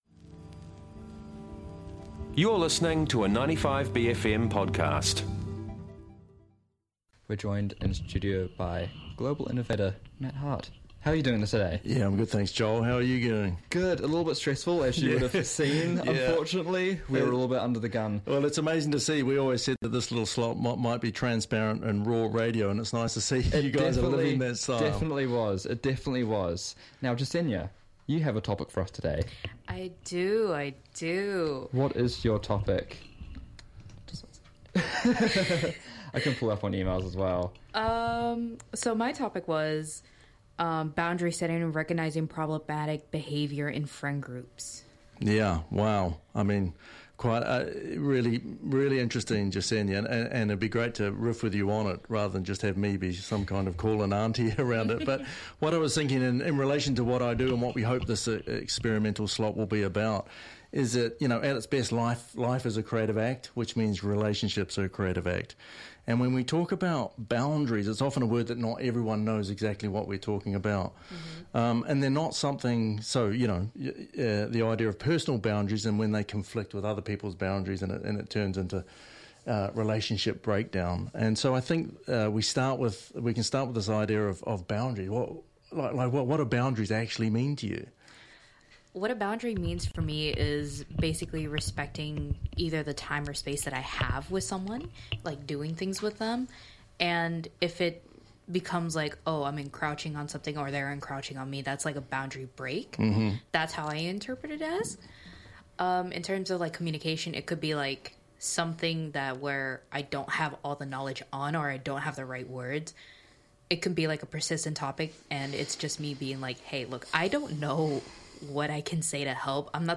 asked MP Katie Nimon about each of these issues, starting off with changes to the Fast Track Approvals Bill.